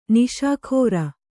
♪ niśa khōra